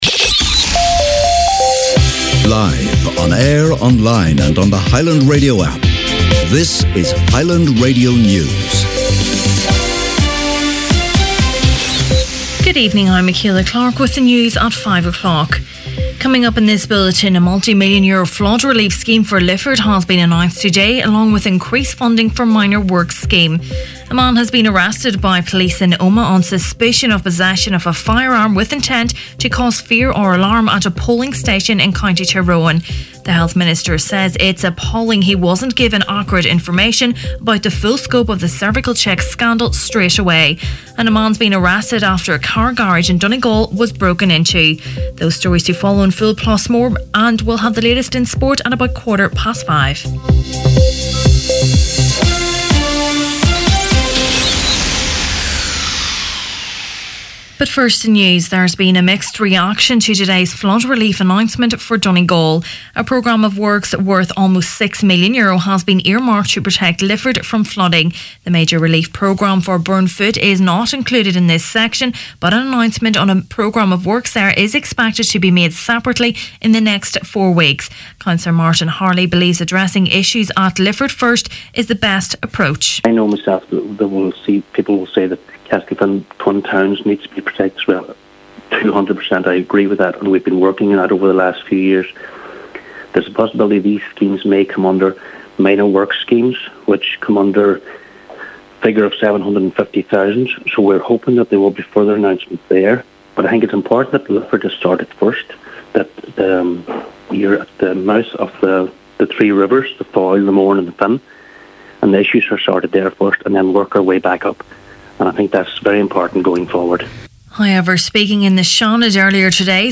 Main Evening News, Sport and Obituaries May 3rd